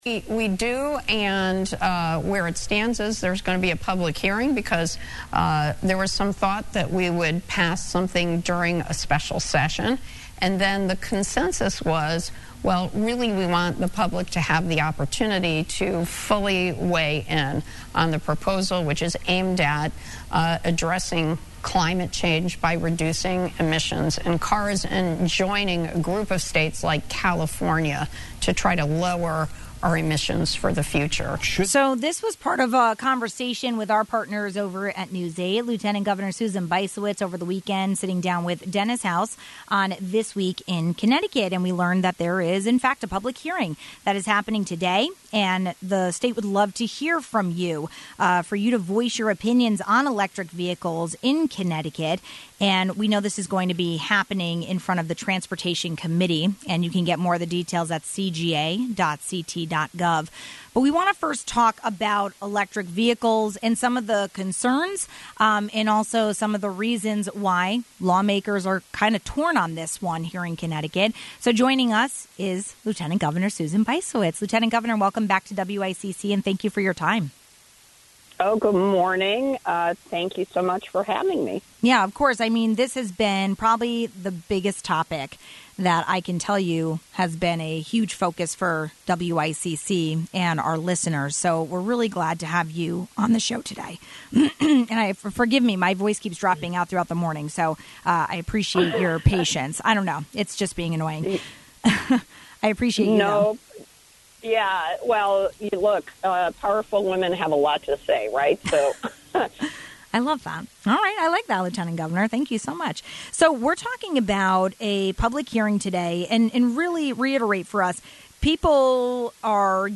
We had a deeper conversation about this with Lt. Governor Susan Bysiewicz ahead of a major public hearing today on EVs.